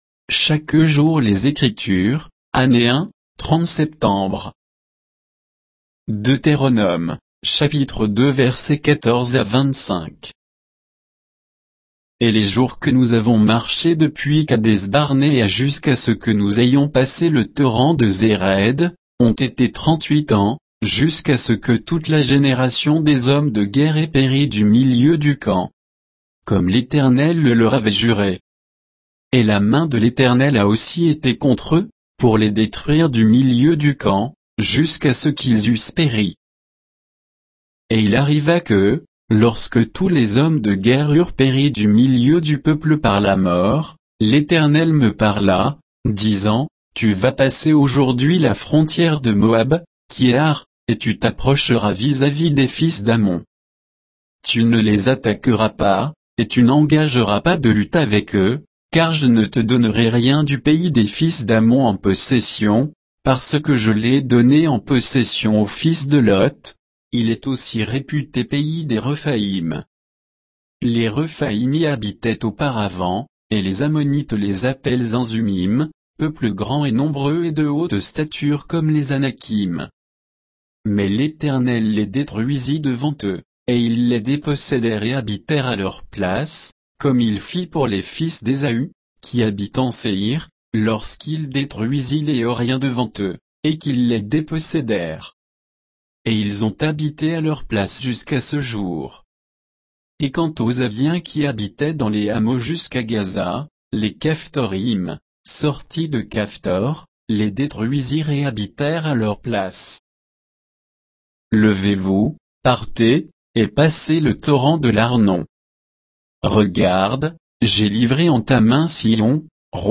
Méditation quoditienne de Chaque jour les Écritures sur Deutéronome 2, 14 à 25